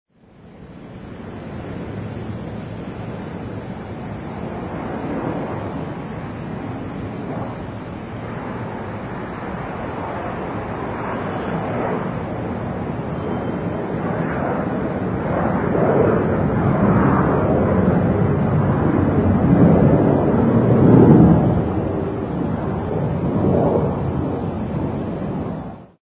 دانلود صدای فرودگاه بزرگ و فرودآمدن هواپیما از ساعد نیوز با لینک مستقیم و کیفیت بالا
جلوه های صوتی